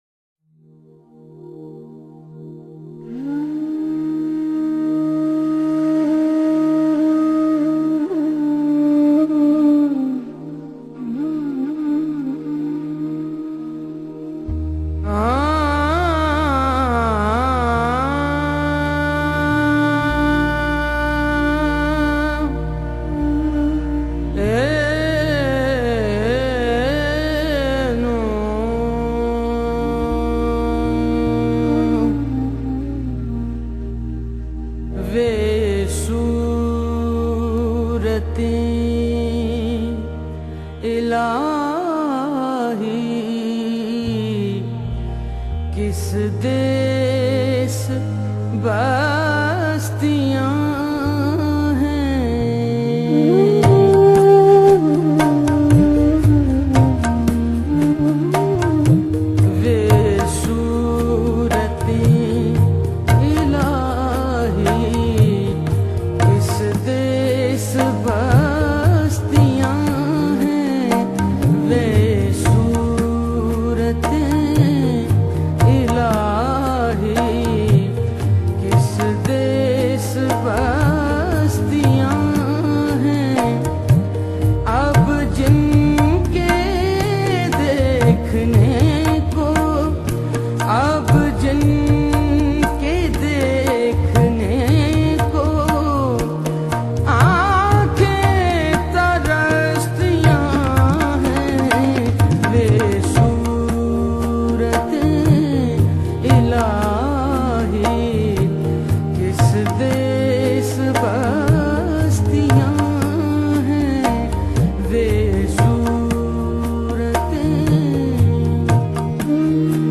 Recitation
abida parveen